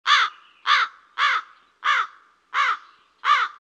crow2.mp3